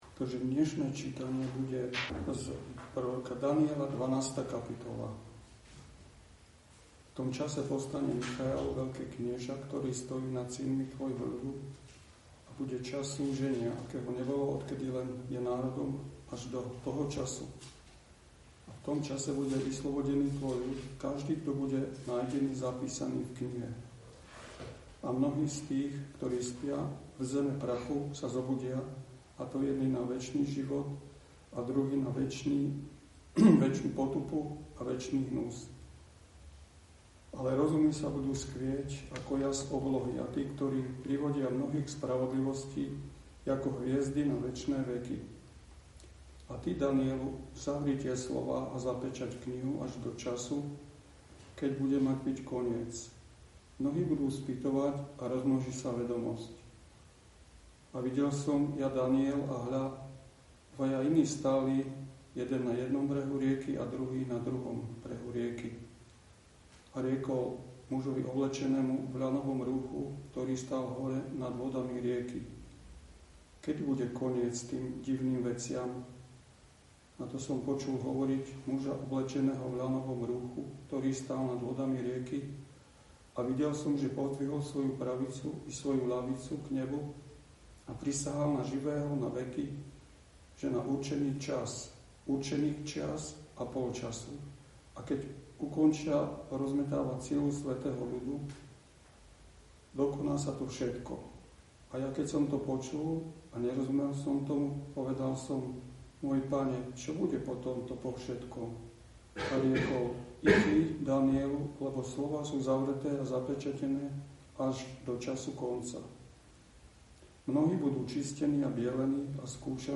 Miesto: Bratislava